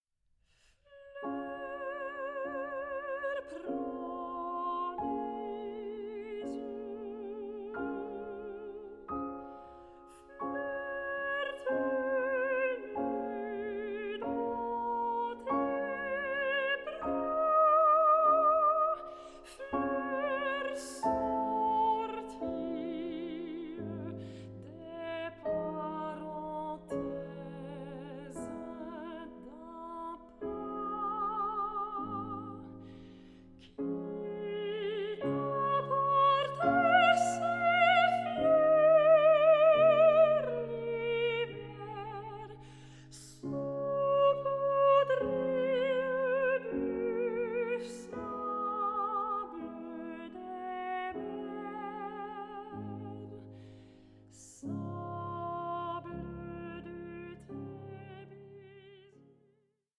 GERMAN AND FRENCH SONGS ON LOVE AND LOSS